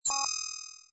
ui_secondary_window_open.wav